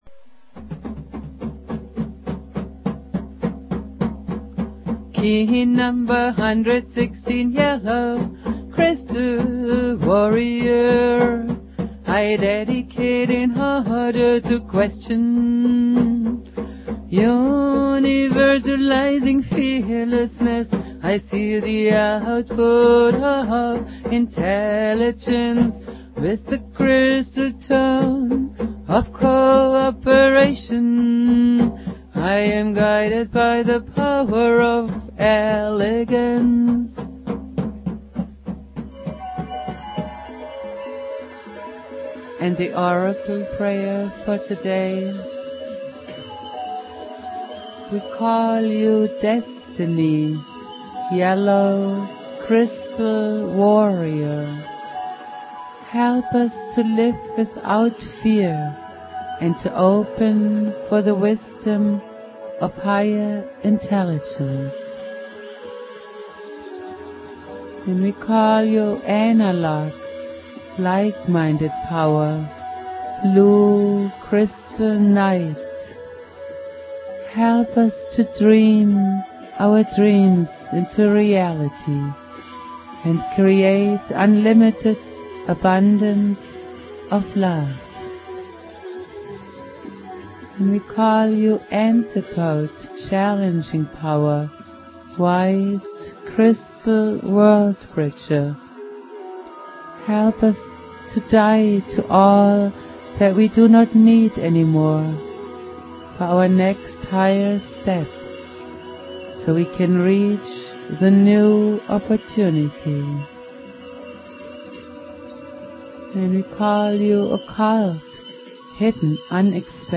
Prayer
produced at High Flowing Recording Studio
Jose's spirit and teachings go on Jose Argüelles playing flute.